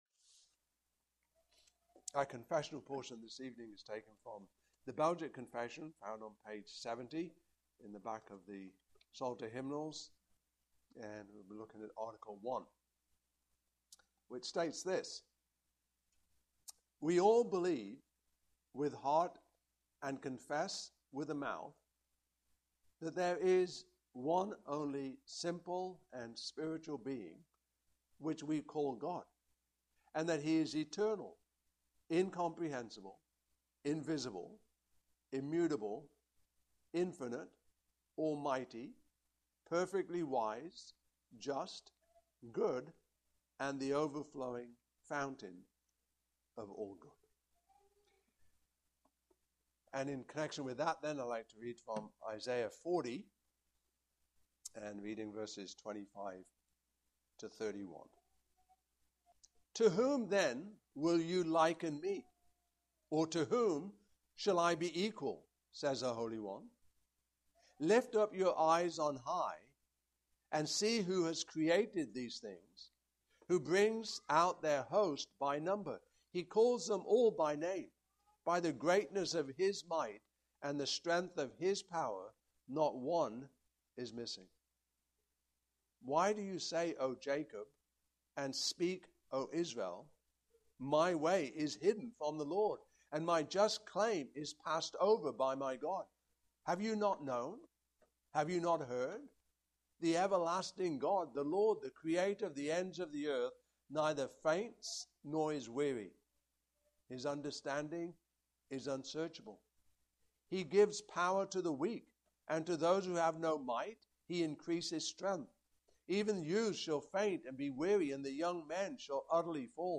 Passage: Isaiah 40:25-31 Service Type: Evening Service Topics